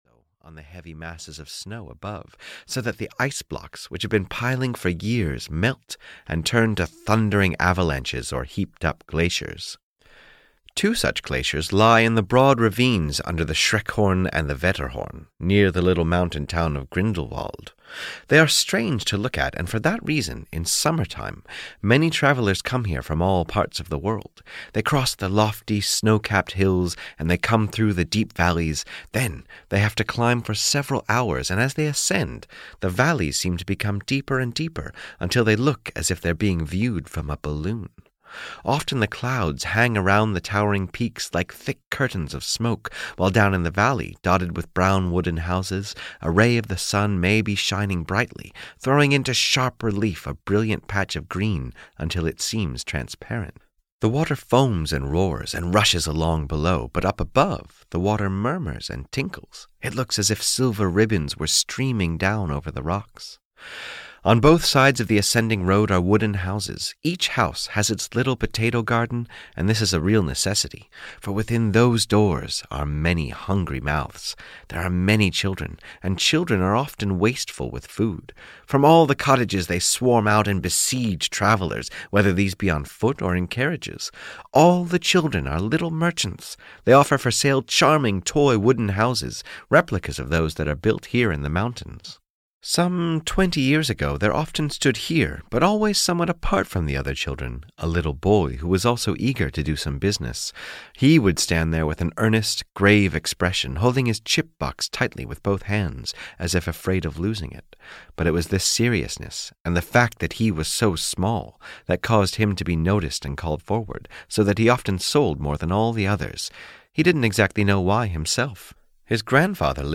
The Ice Maiden (EN) audiokniha
Ukázka z knihy